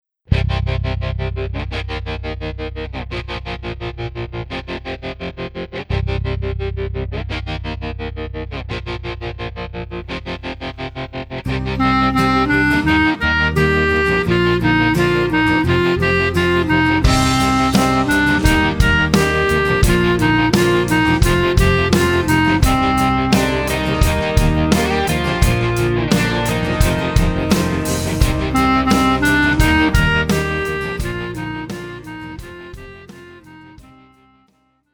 Alto Saxophone and Piano Level